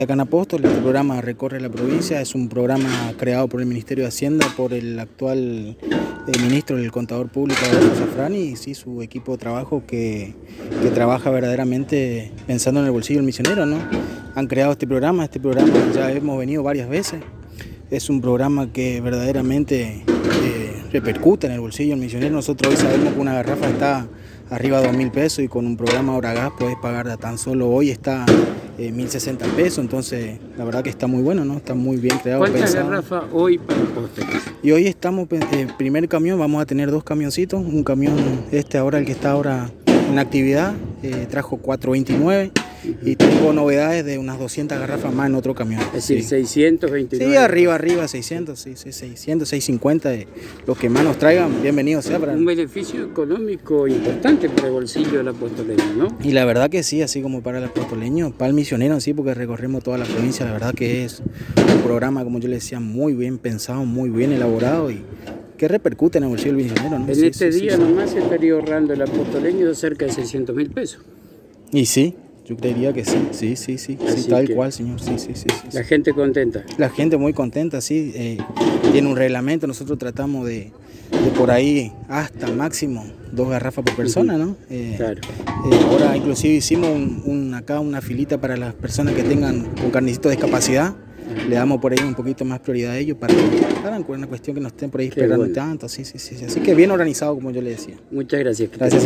En diálogo exclusivo con la ANG